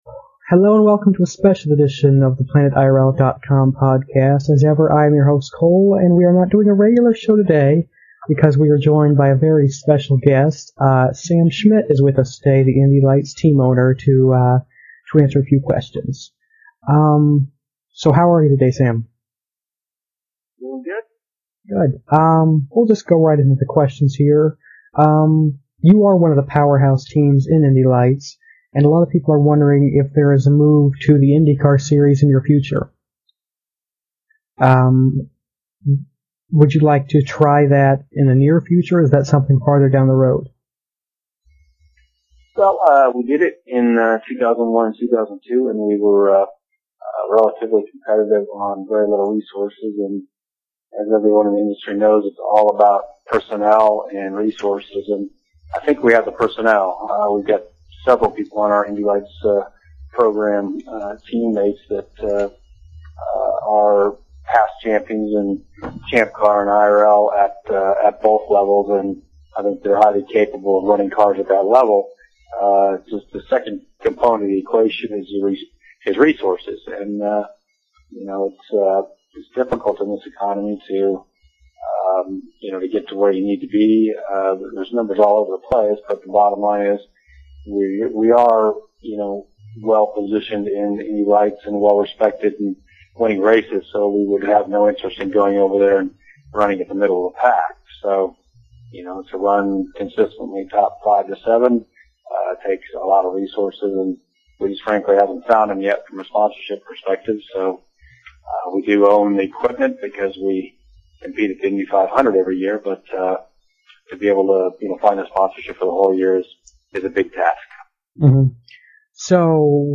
An interview with Sam Schmidt and James Hinchcliffe
In this interview, I talk with Indy Lights team owner Sam Schmidt and his driver, James Hinchcliffe.